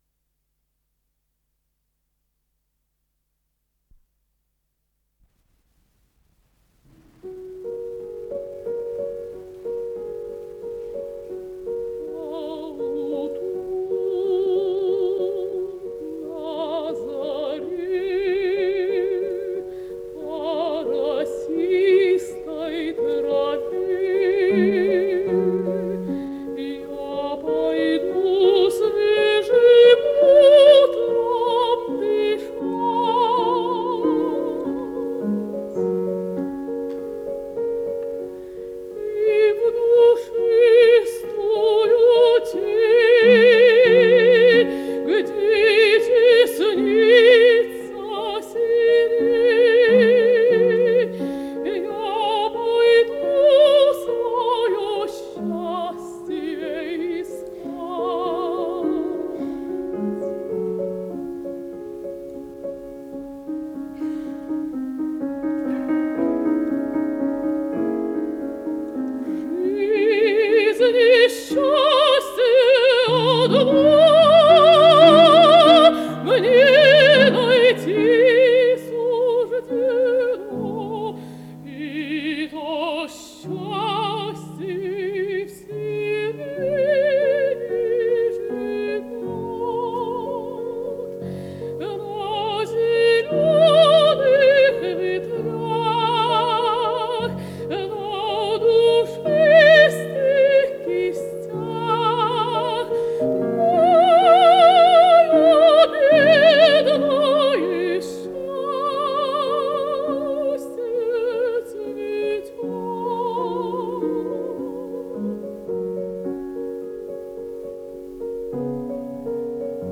с профессиональной магнитной ленты
ИсполнителиЕлена Образцова - пение
фортепиано